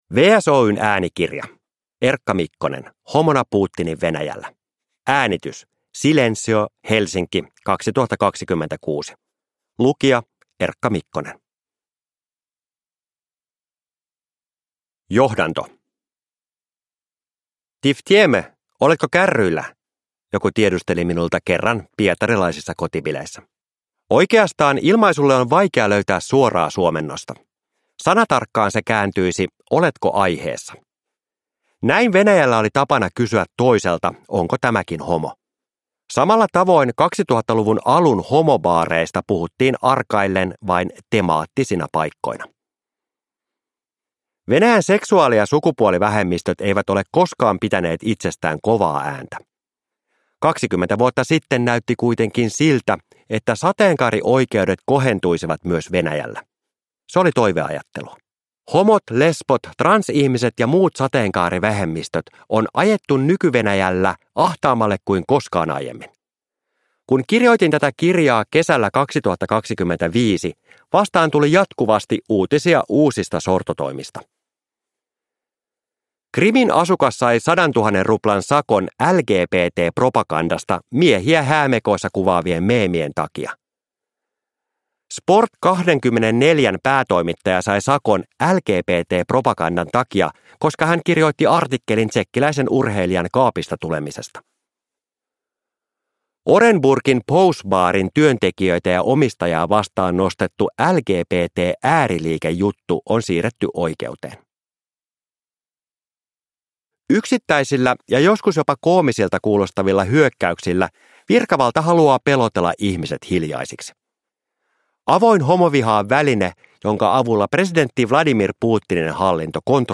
Homona Putinin Venäjällä – Ljudbok